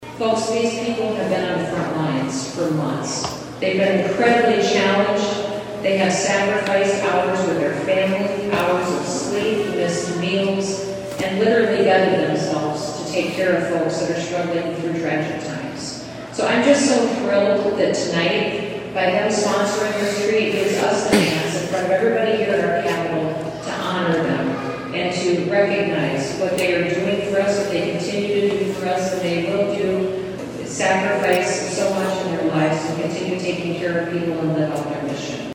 The Christmas at the Capitol holiday display in Pierre kicked off with the Grand Tree Lightning Ceremony in the Capitol Rotunda last night (Tues.).